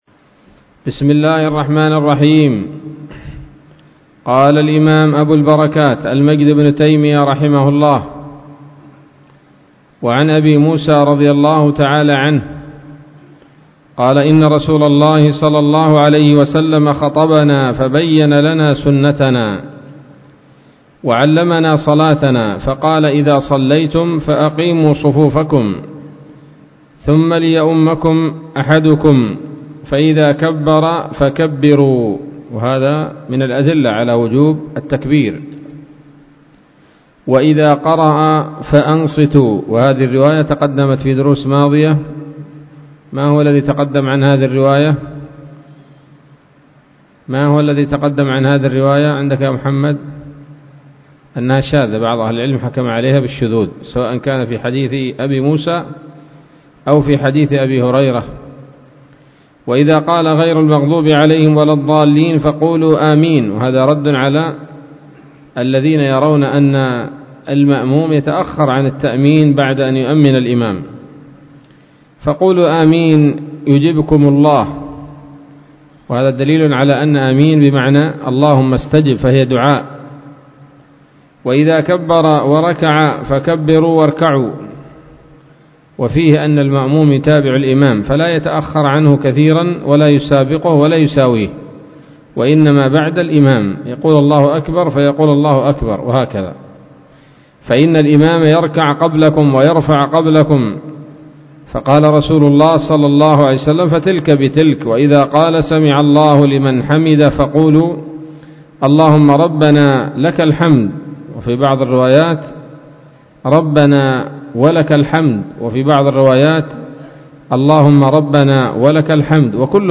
الدرس الثالث والخمسون من أبواب صفة الصلاة من نيل الأوطار